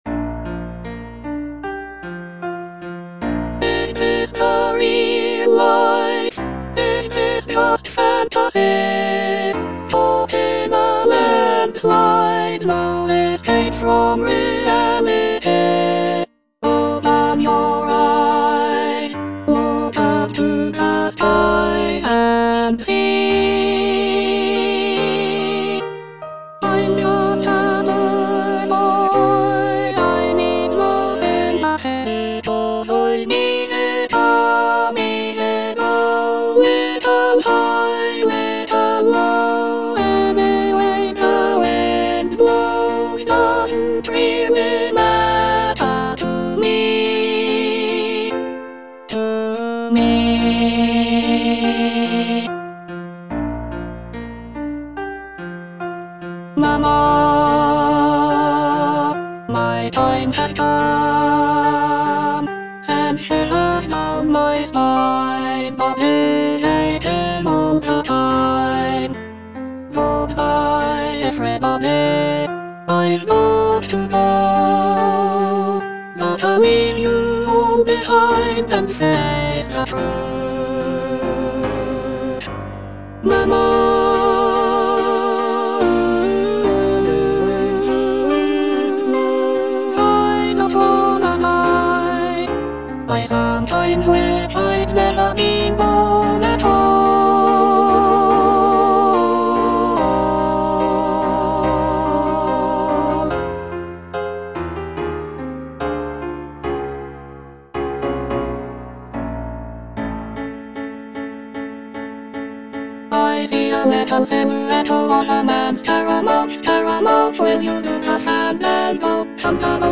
Soprano I (Emphasised voice and other voices)